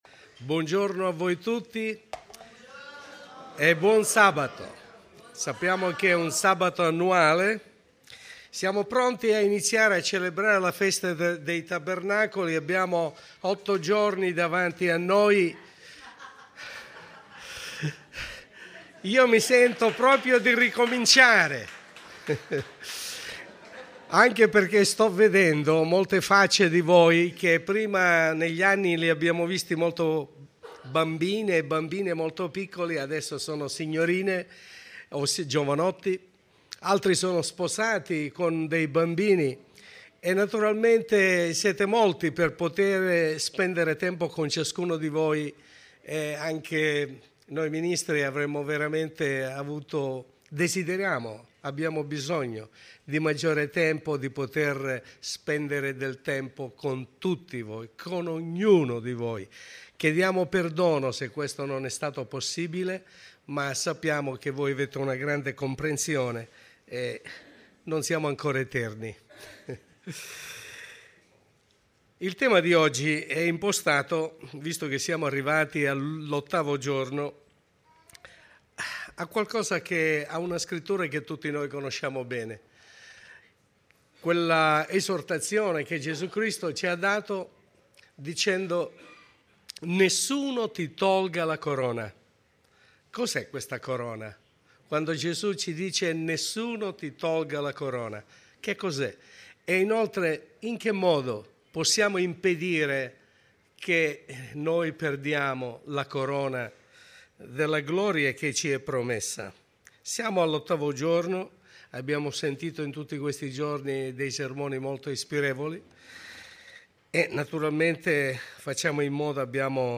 Sermone